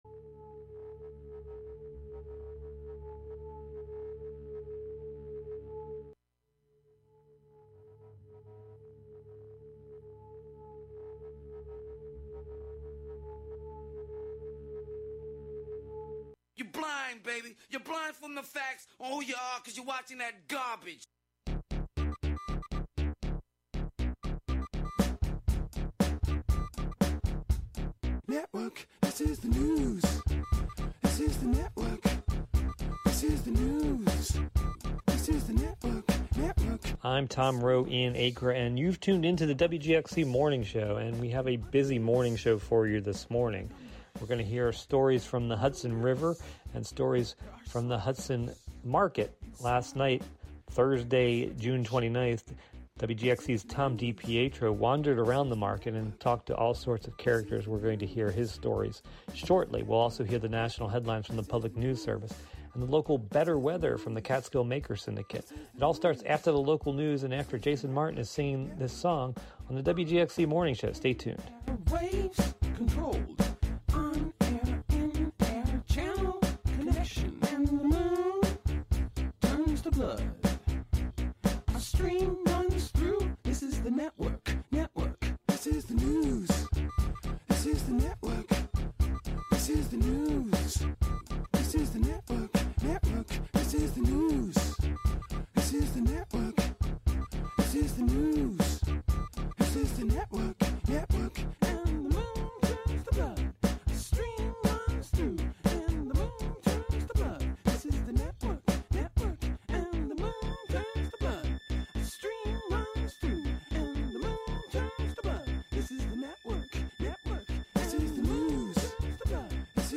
vocal ceremony